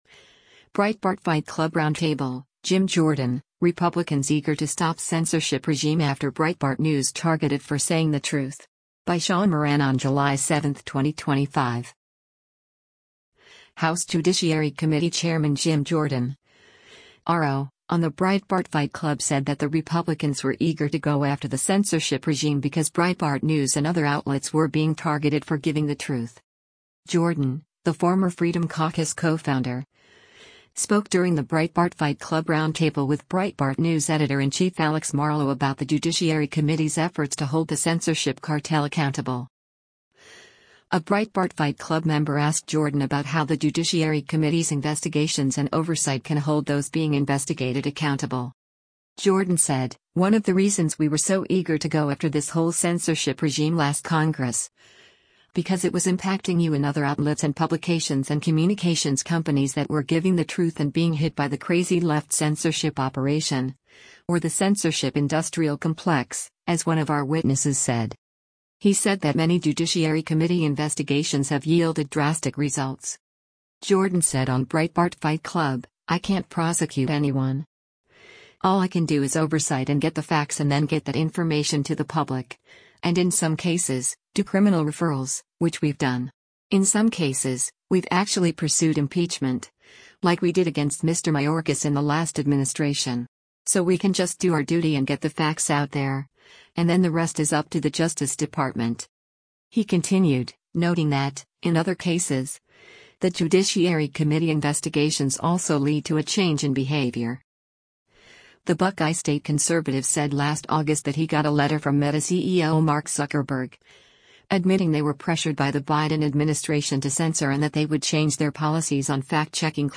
A Breitbart Fight Club member asked Jordan about how the Judiciary Committee’s investigations and oversight can hold those being investigated accountable.